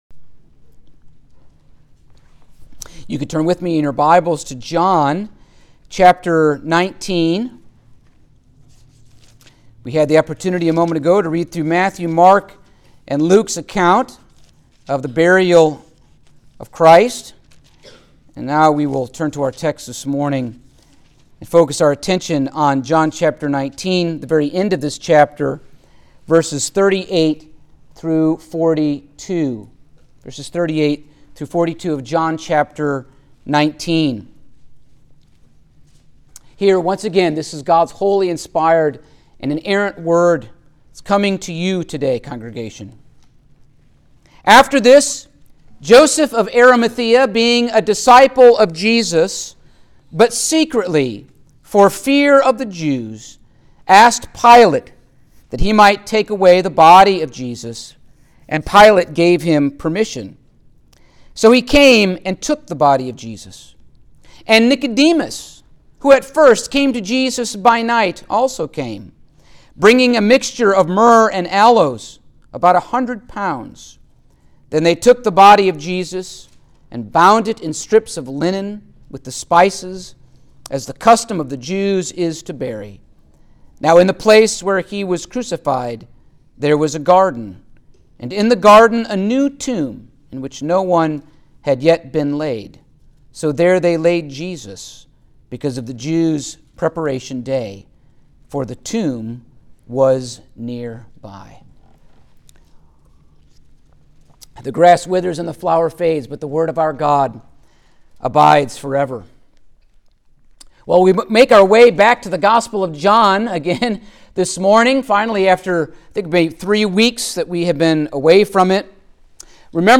Passage: John 19:38-42 Service Type: Sunday Morning